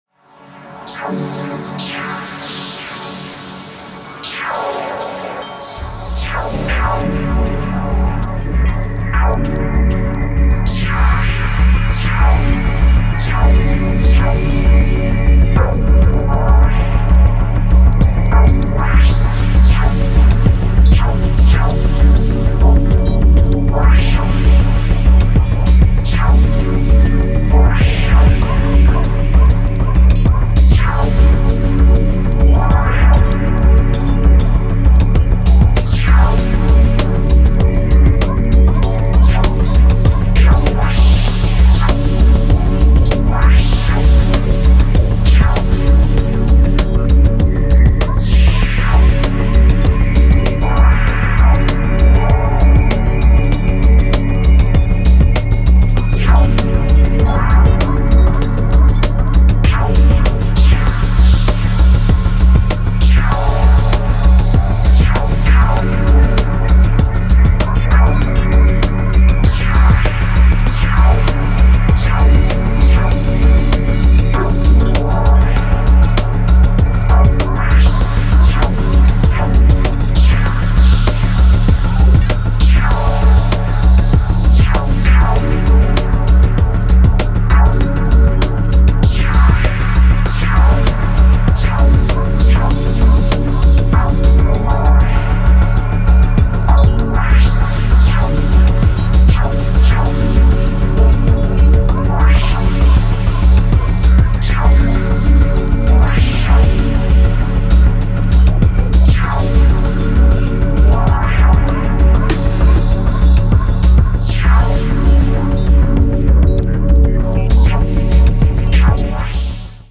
Downtempo Dub Techno